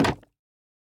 Minecraft Version Minecraft Version 1.21.5 Latest Release | Latest Snapshot 1.21.5 / assets / minecraft / sounds / block / bamboo_wood_trapdoor / toggle2.ogg Compare With Compare With Latest Release | Latest Snapshot